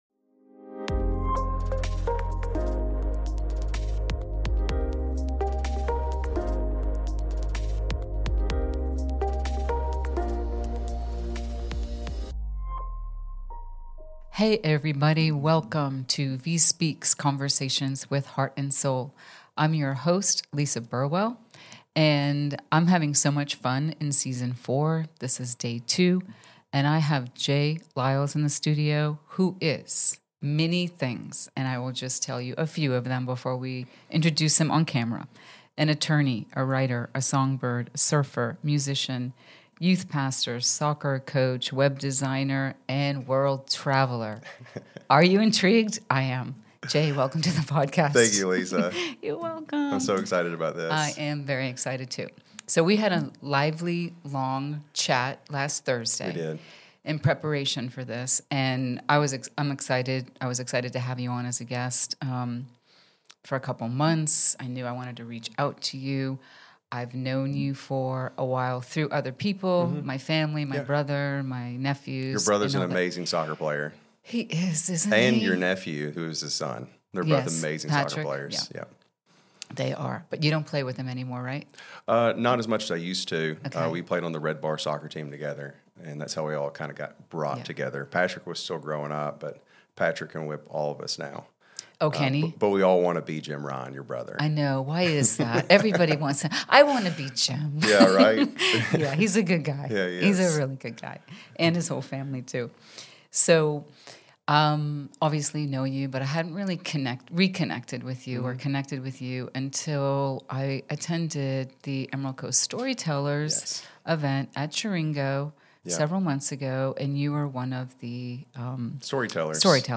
71: "Catching One More Wave" - A Conversation